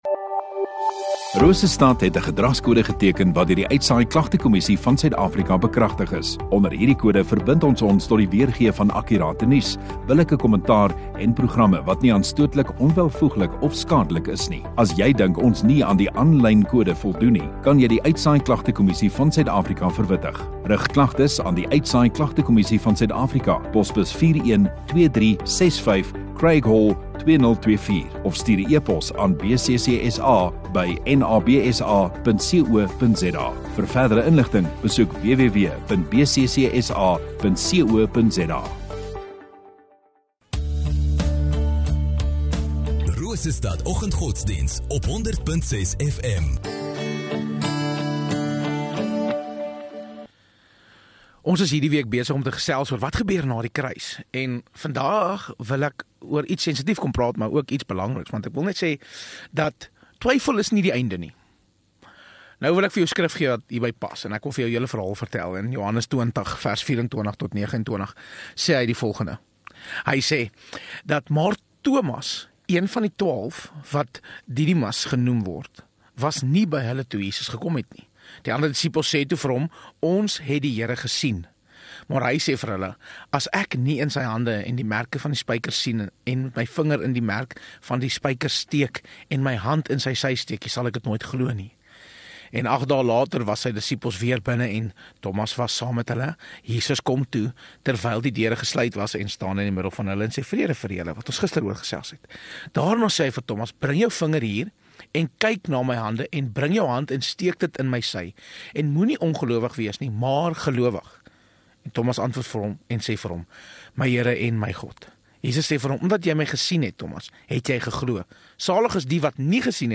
15 Apr Woensdag Oggenddiens